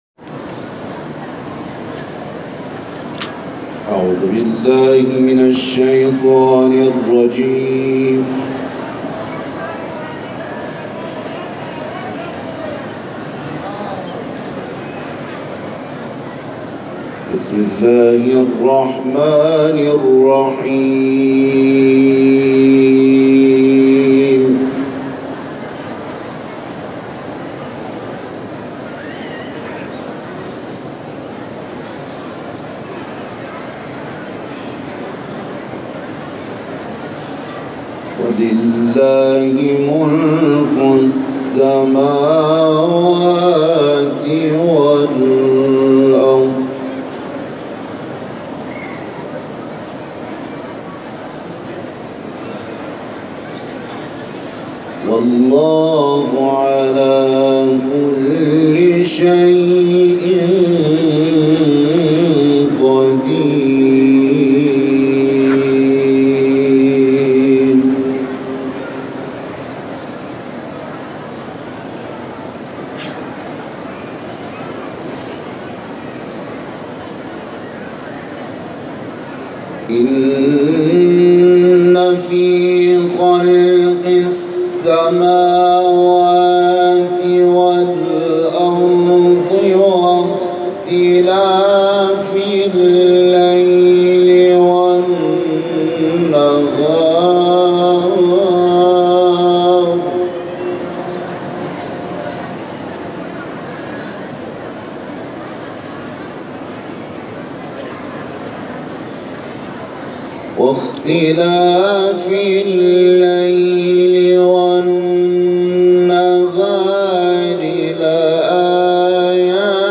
تلاوت
در حرم مطهر رضوی ارائه می‌شود که آیات پایانی سوره آل عمران تلاوت شده است.